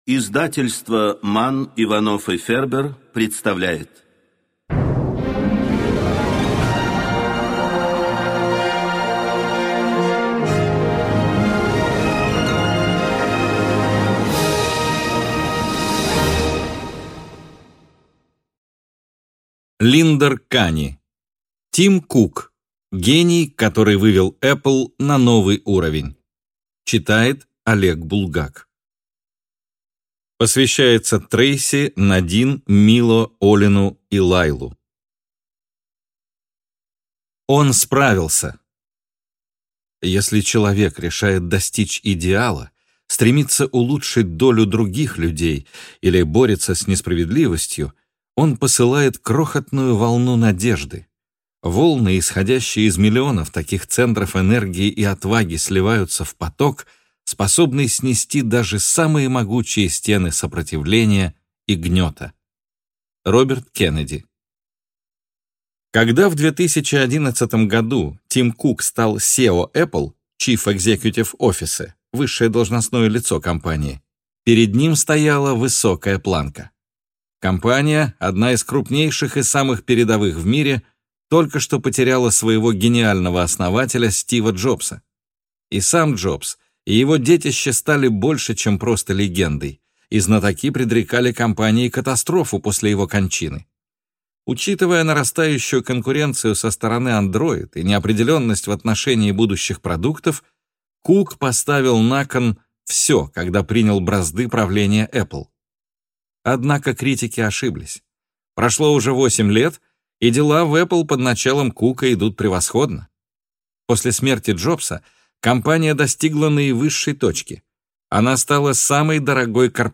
Аудиокнига Тим Кук | Библиотека аудиокниг